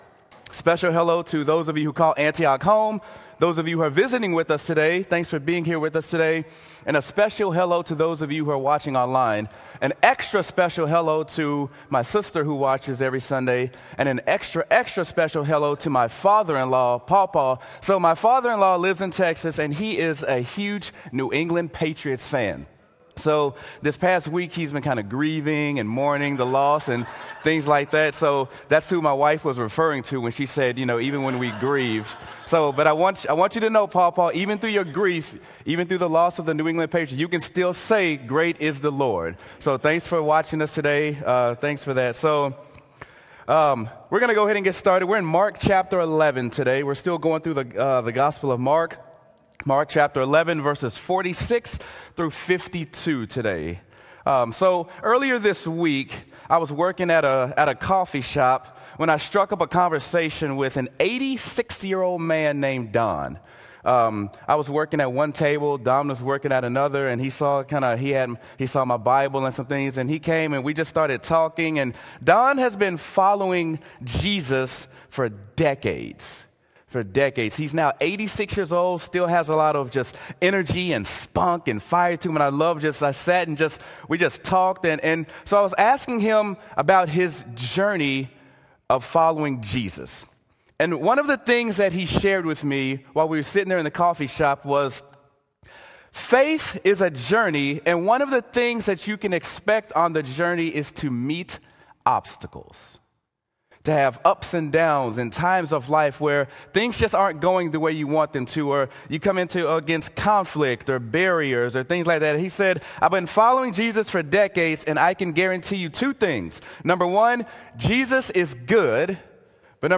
Sermon: Mark: When Faith Meets Obstacles
sermon-mark-when-faith-meets-obstacles.m4a